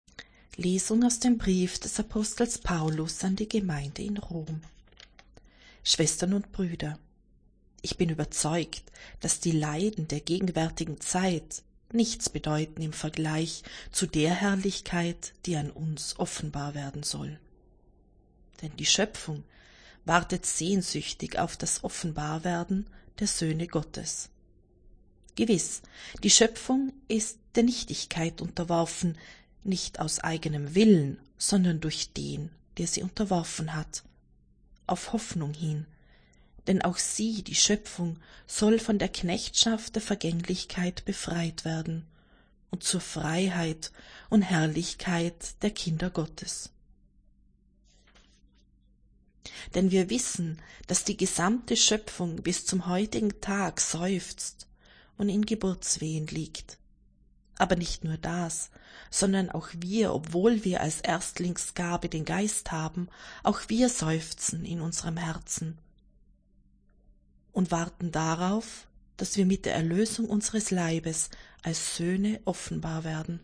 Wenn Sie den Text der 2. Lesung aus dem Brief des Apostels Paulus an die Gemeinde in Rom anhören möchten: